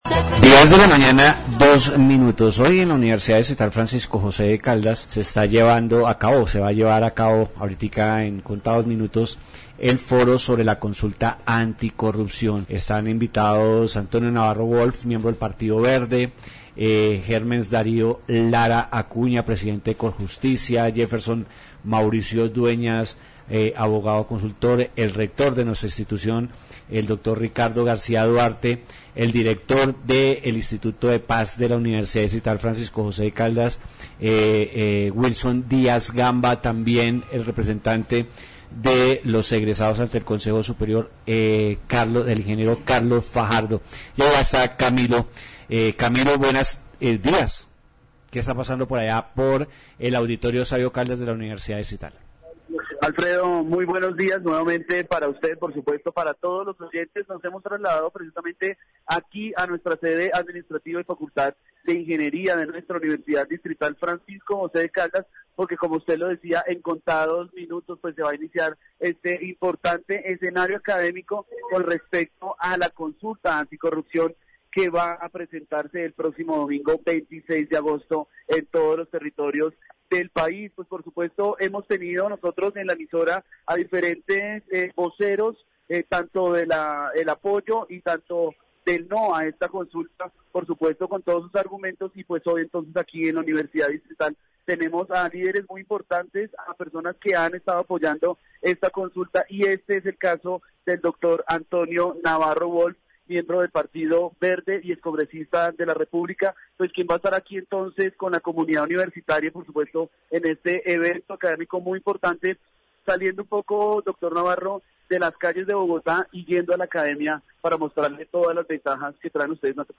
Corrupción -- democracia , Consultas populares , Universidades públicas en Bogotá -- Foro , Programas de Radio , Wolff, Navarro -- Entrevistas